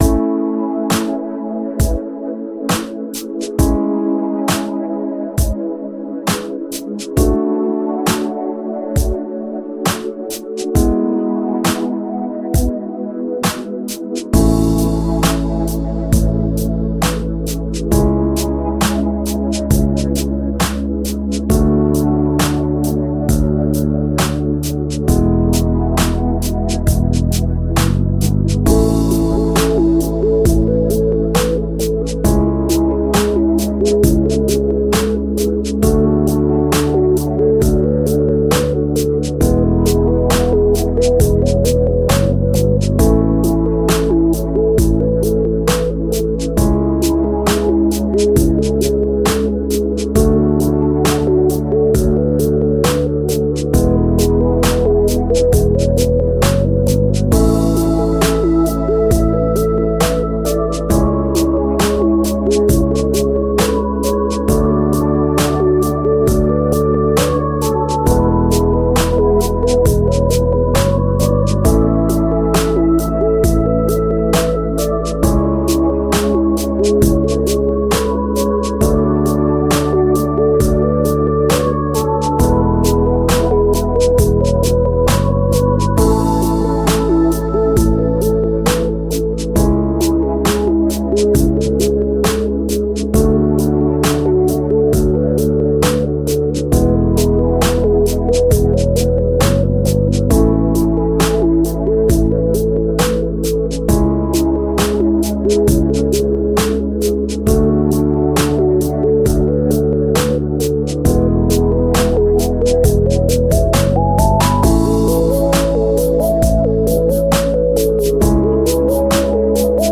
Медленная и расслабляющая фоновая музыка для видео на ютубе